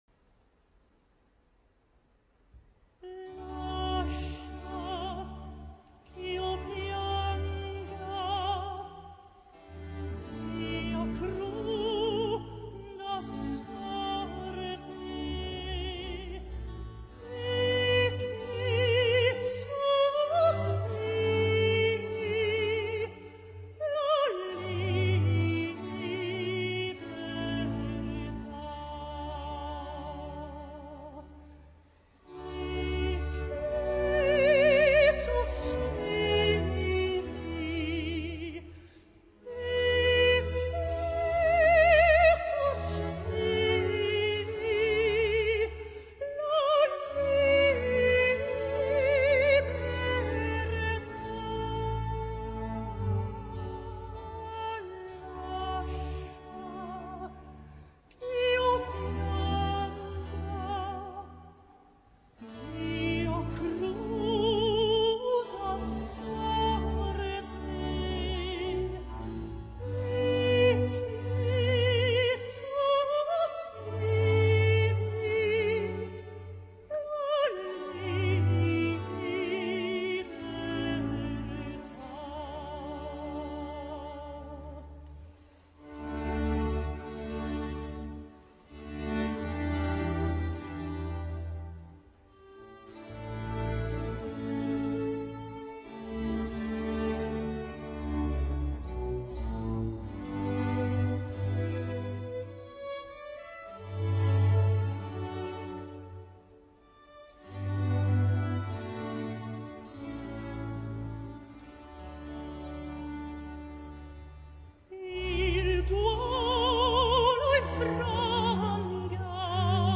Tipos de Contratenores - Cantá Lírico
Philippe Jauroussky Se caracteriza por una técnica virtuosista de melisma para animar y obligar a la interpretación de cantatas barrocas y la ópera.
Philippe-Jaroussky-Lascia-chio-pianga-Handel-BEST-QUALITY_KxnBjAaJWCc.mp3